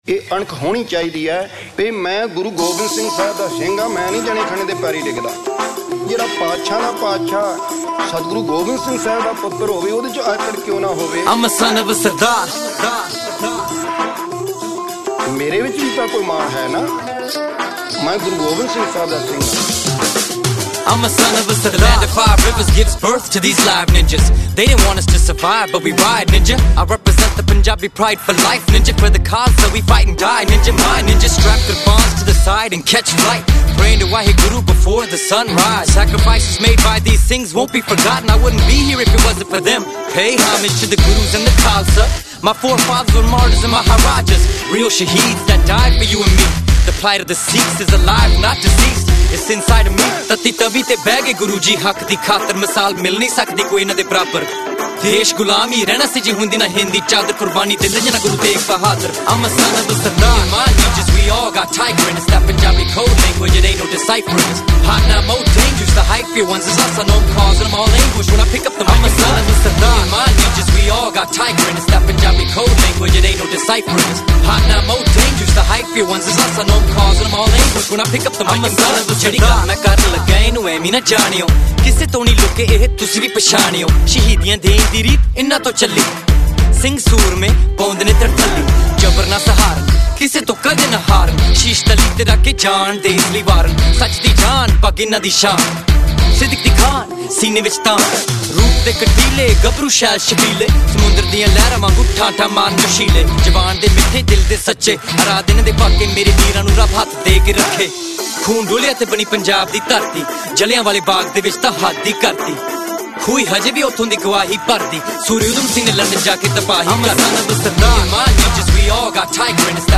Genre: Sikh Song